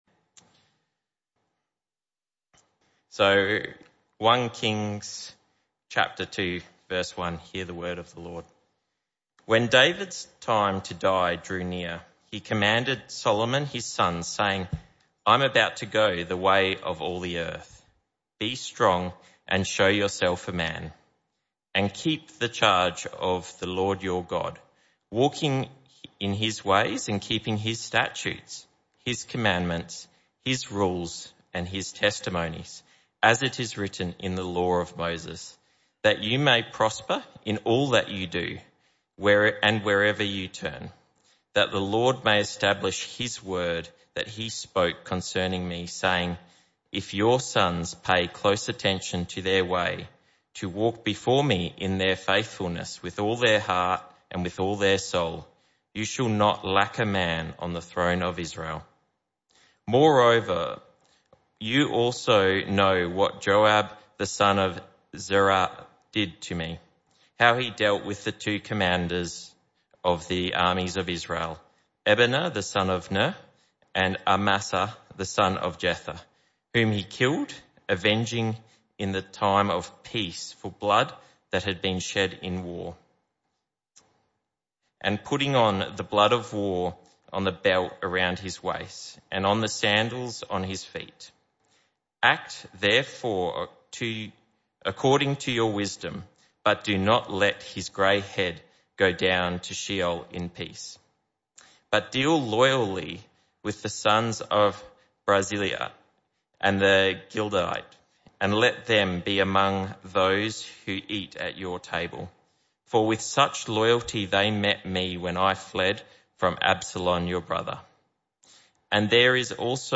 Weekly sermons, other bible talks and occasional special events from WPC Bull Creek